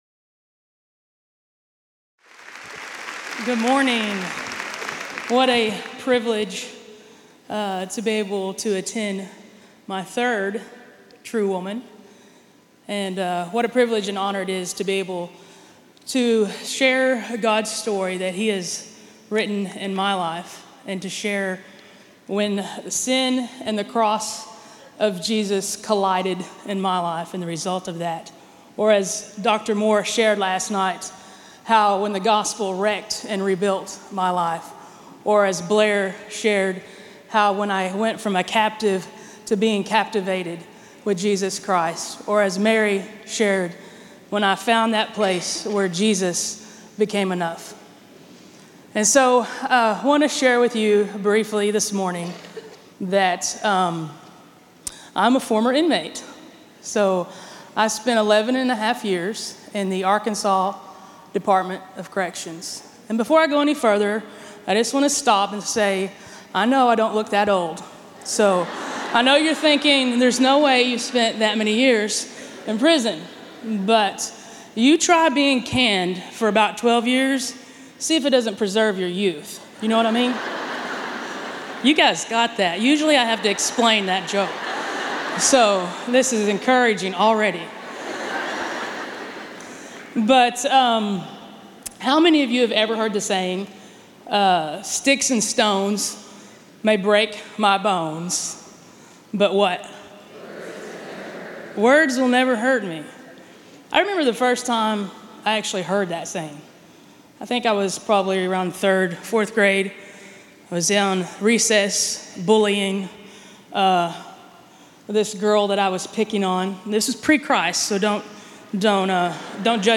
Finding Christ in an Unlikely Place | True Woman '16 | Events | Revive Our Hearts